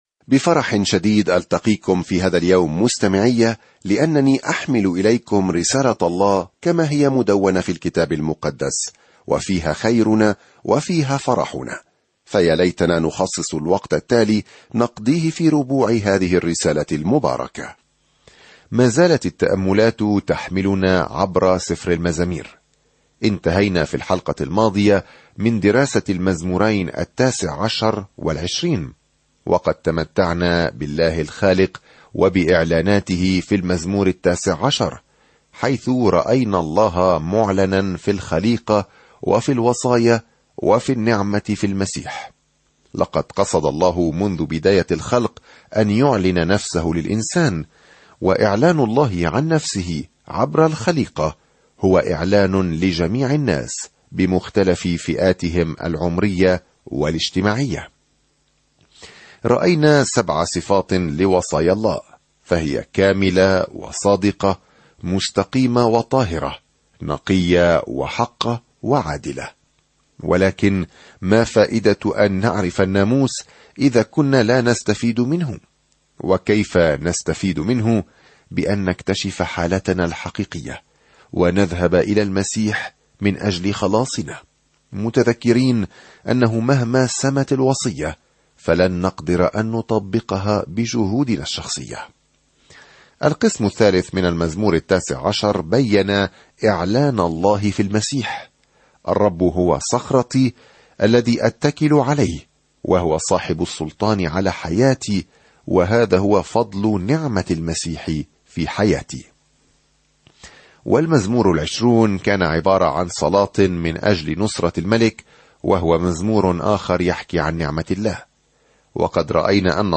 سافر يوميًا عبر المزامير وأنت تستمع إلى الدراسة الصوتية وتقرأ آيات مختارة من كلمة الله.